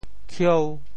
How to say the words 喬 in Teochew？
khieu5.mp3